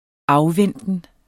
Udtale [ ˈɑwˌvεnˀdən ]